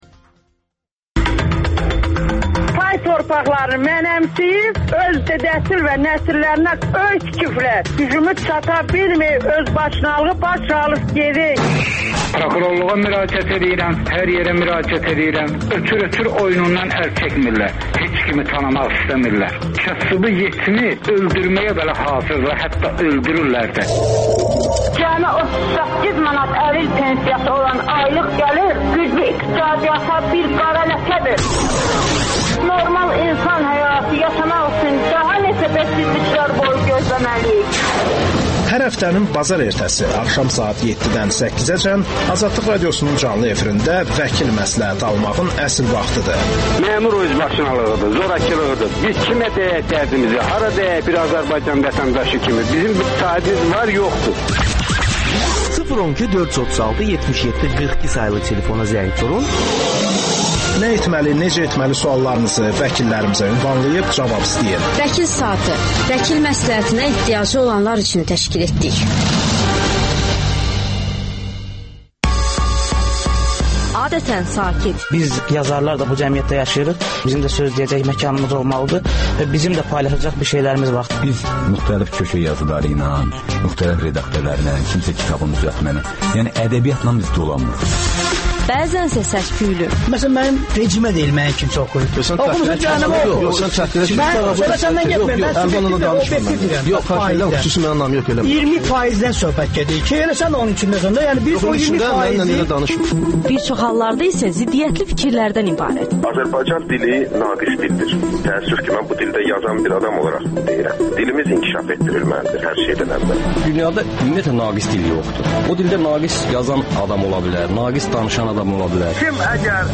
«Qaynar xətt» telefonunda dinləyicilərin suallarına hüquqşünaslar cavab verir.